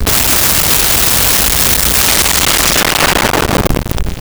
Explosion Large Slight Debris
Explosion Large Slight Debris.wav